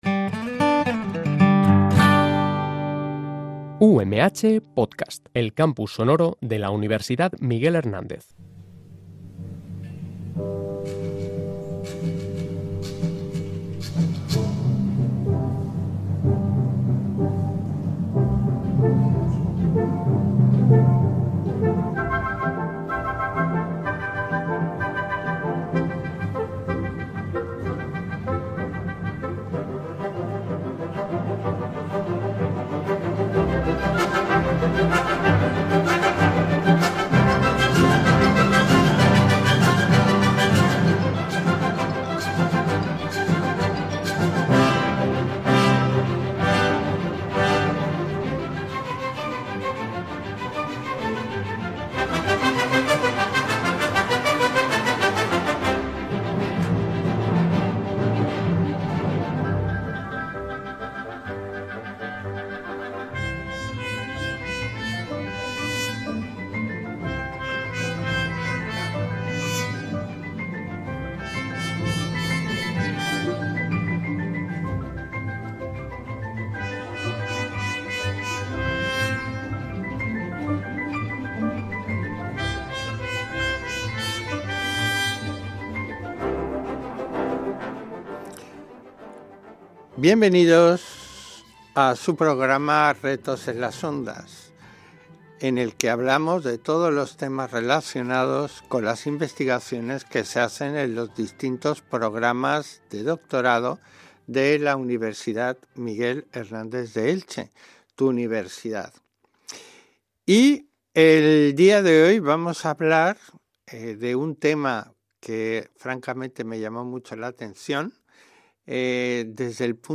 Una interesante entrevista donde nos explican el valor de estos compuestos en la mejora de calidad de estos frutos tan característicos y de enorme implicación económica y social.